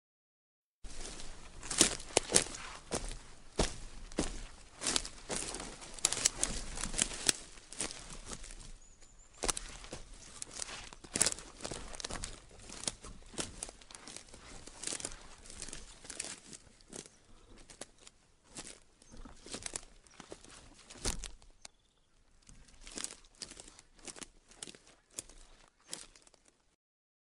Ahora bien, si su personaje camina por un pasillo, sus pasos van a tener distintos niveles de intensidad a medida que se vaya alejando o acercando.
Ejemplo de cambio de volumen
ejemplo-cambio-de-volumen-relatos-sonoros.wav